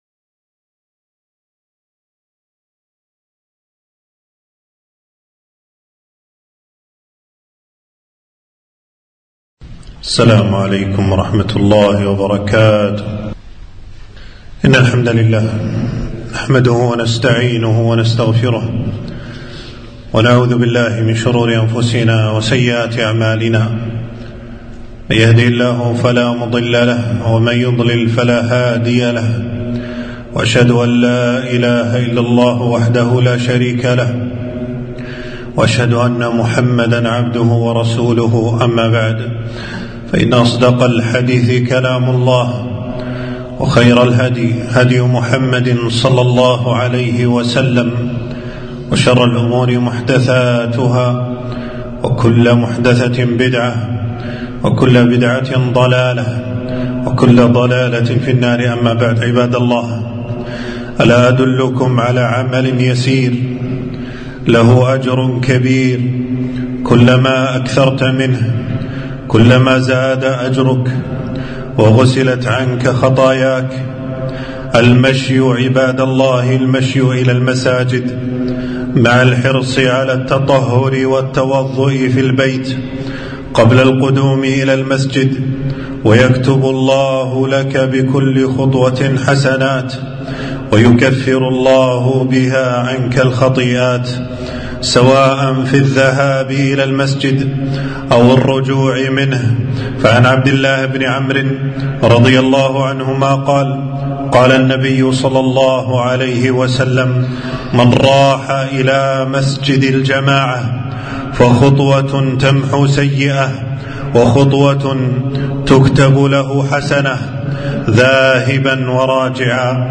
خطبة - عمل يسير له أجر كبير (كثرة الخُطا إلى المساجد)